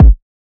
MZ Kick [Yeezy's].wav